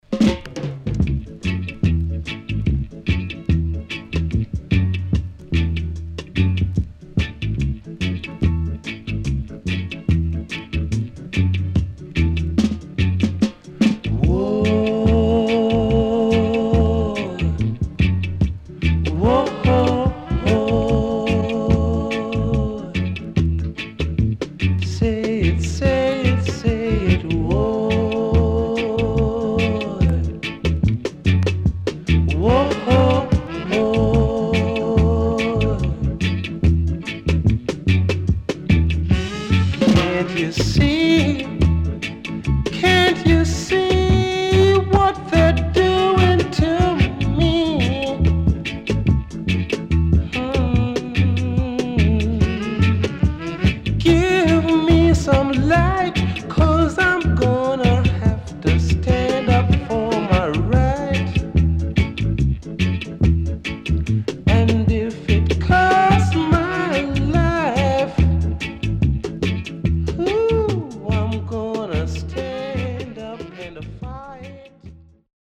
Good Vocal
SIDE A:少しチリノイズ、プチノイズ入ります。